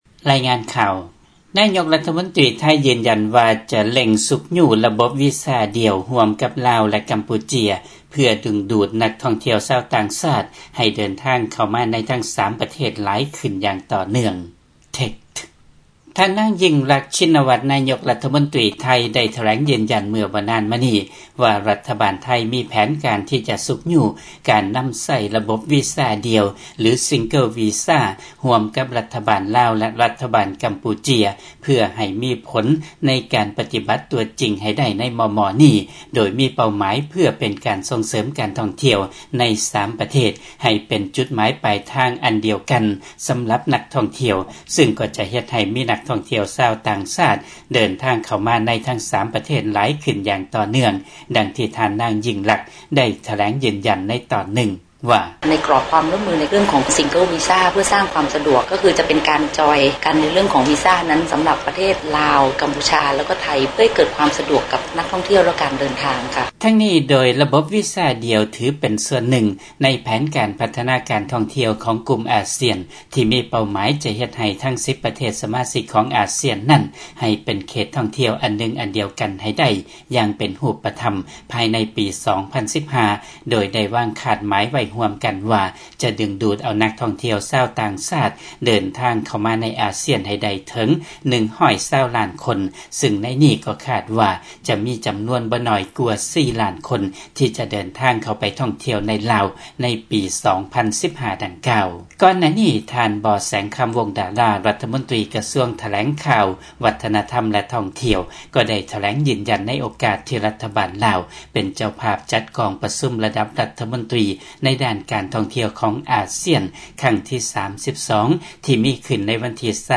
ຟັງລາຍງານການກູ້ຢືມເງິນຂອງລັດຖະບານລາວ